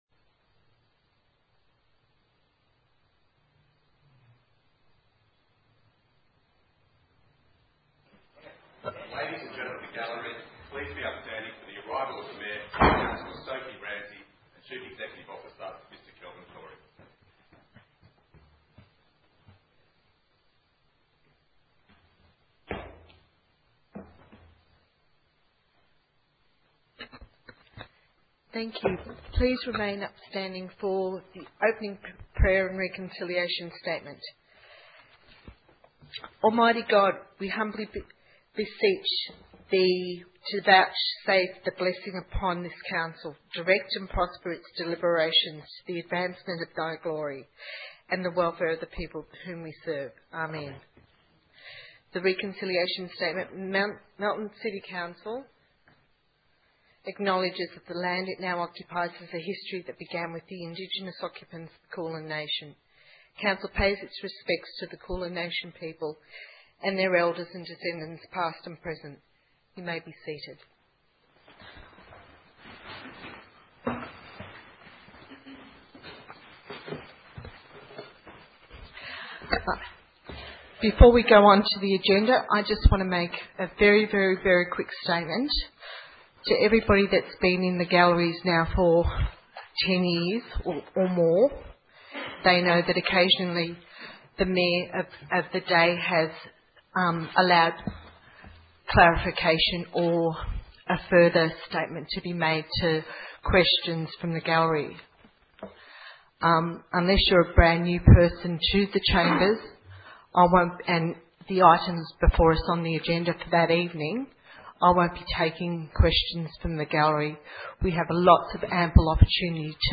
18 November 2014 - Ordinary Council Meeting